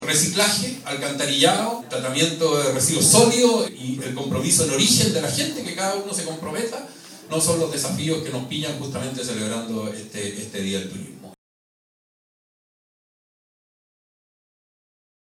En el acto inaugural del jueves 27, con la asistencia del alcalde Freddy Ibacache, el Concejo Municipal en pleno y representantes de SERNATUR, entre otras autoridades, alumnos/as de la carrera de servicios turísticos motivaron a los asistentes a reciclar, ahorrar energía y a ser amigables y honrados con los turistas, para potenciar este eje de desarrollo de Hualaihué de manera sustentable.
Escuche al alcalde Freddy Ibacache aquí.
Freddy_Ibacache_Turismo.mp3